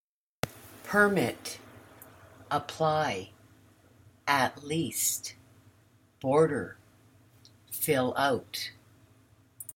How-to-get-a-Visa-to-study-English-abroad-Vocabulary.mp3